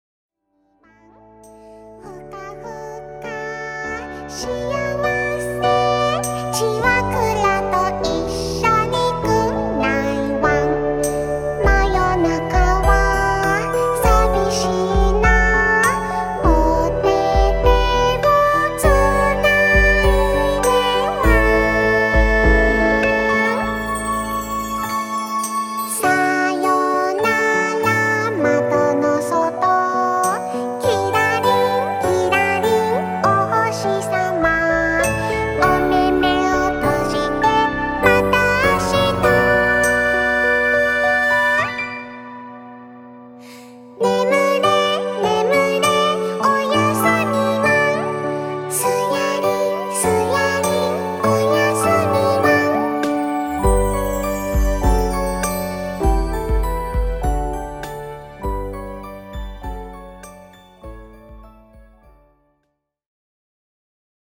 ラップ、音頭、マンボとお祭り騒ぎが続いていましたが、
おやすみを言うような優しい気持ちでつくりました。
優美で暖かくて心がほっとするようなアレンジで、とても素敵な曲になりました！
寝る前に聴いていただきたい子守唄です♪